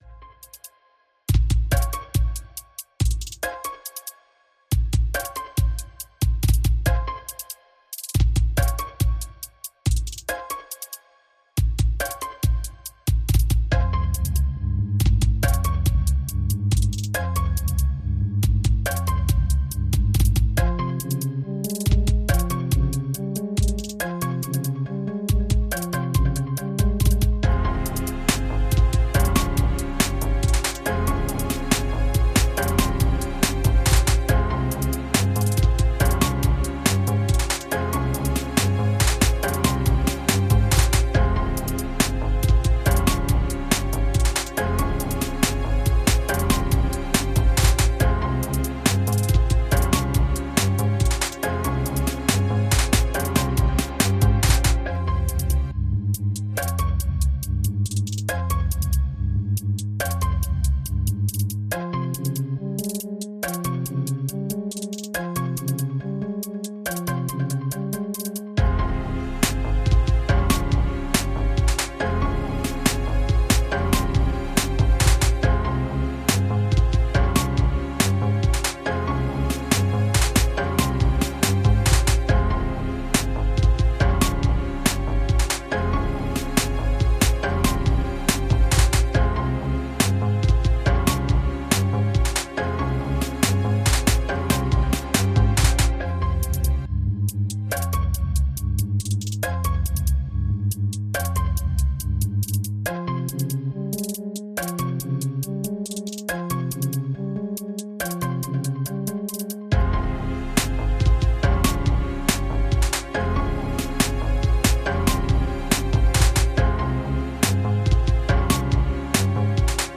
Musique Rap, trap, boombap libre de droit pour vos projets.